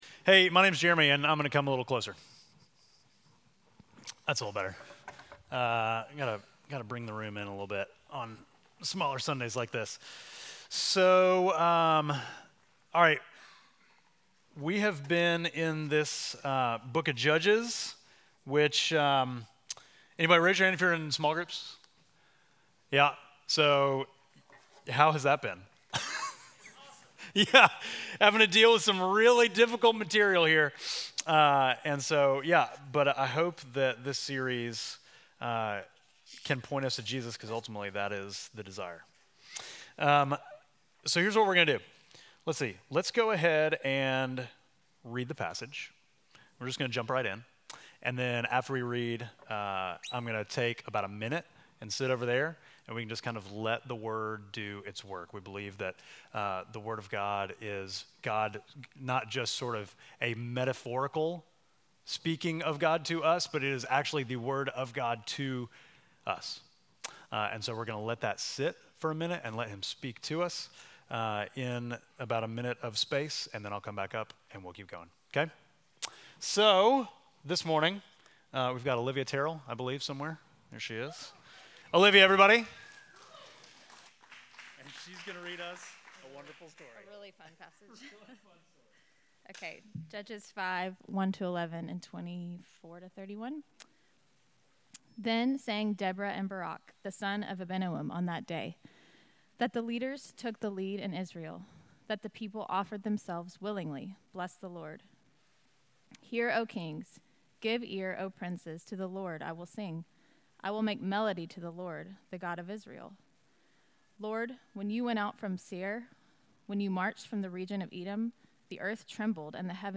Midtown Fellowship Crieve Hall Sermons Jael: The Just Savior Oct 06 2024 | 00:42:03 1x 00:00 / 00:42:03 Subscribe Share Apple Podcasts Spotify Overcast RSS Feed Share Link Embed